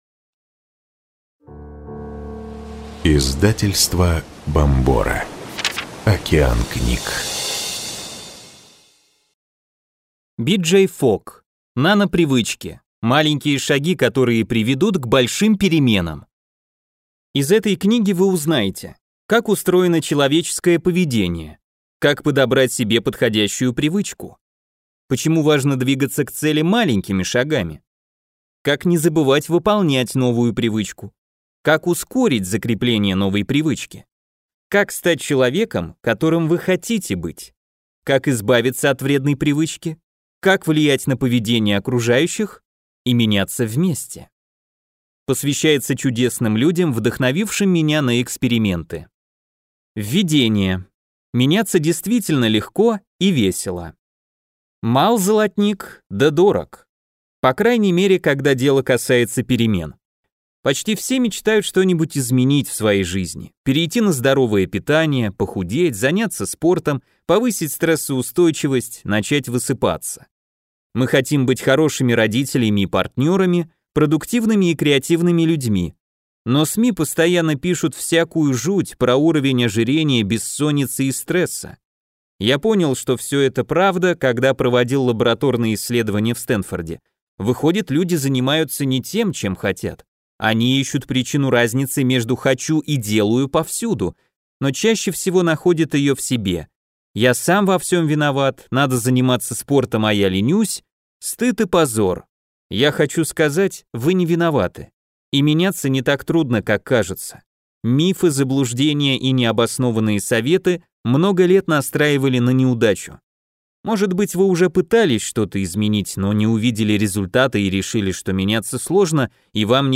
Аудиокнига Нанопривычки. Маленькие шаги, которые приведут к большим переменам | Библиотека аудиокниг